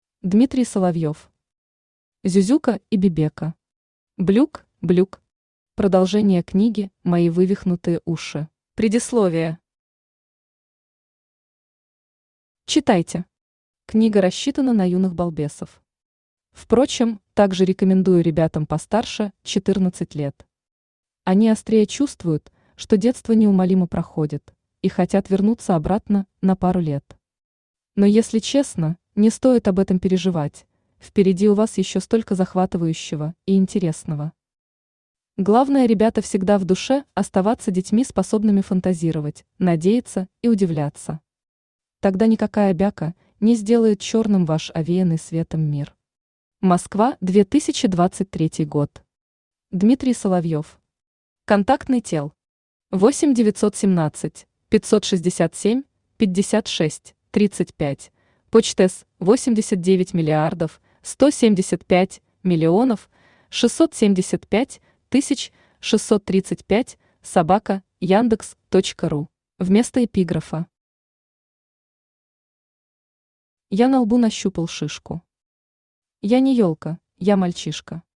Аудиокнига Зюзюка и Бебека… Блюк, Блюк..
Продолжение книги МОИ ВЫВИХНУТЫЕ УШИ Автор Дмитрий Андреевич Соловьев Читает аудиокнигу Авточтец ЛитРес.